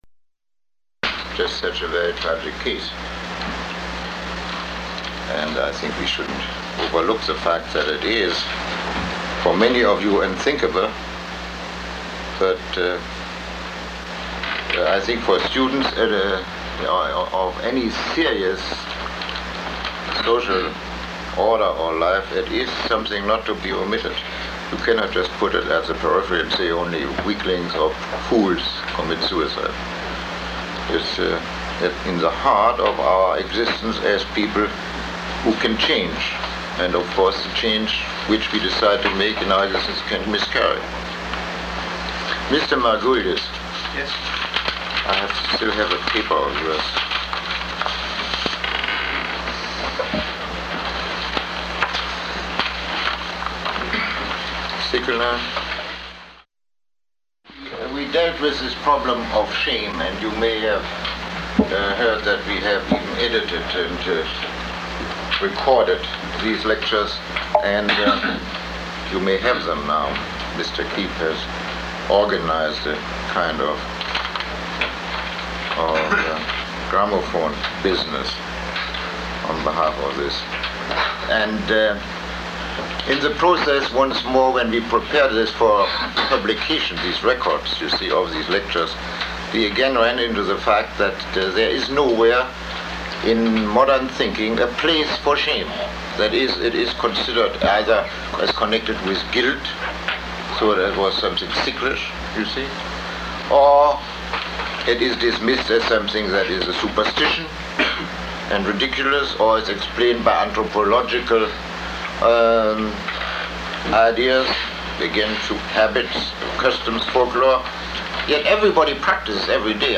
Lecture 21